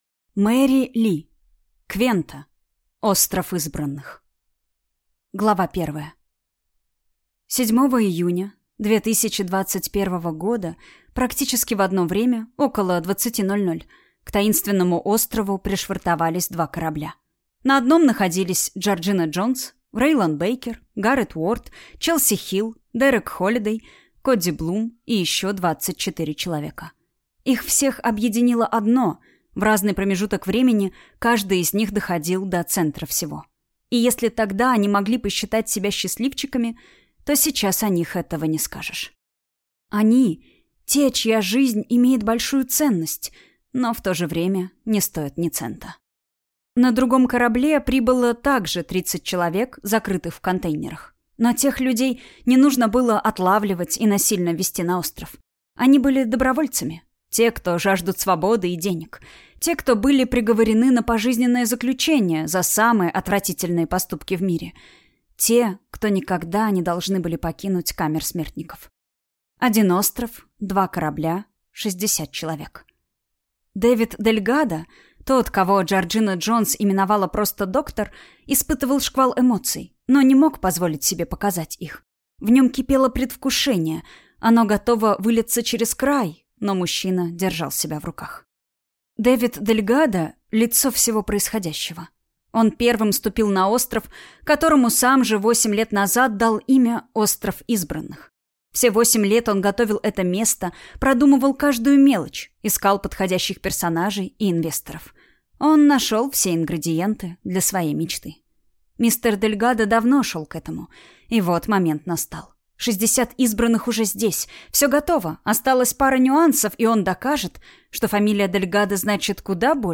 Аудиокнига Квента. Остров Избранных | Библиотека аудиокниг